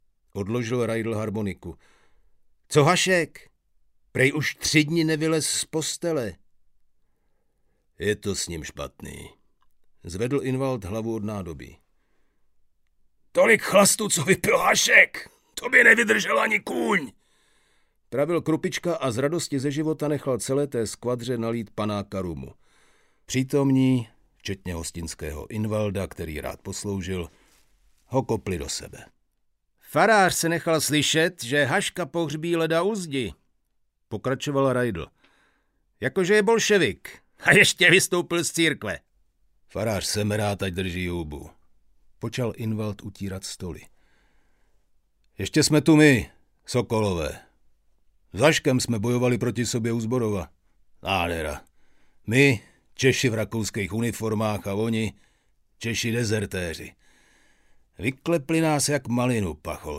Audiobook
Read: Marek Vašut